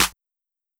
Clap (Headlines).wav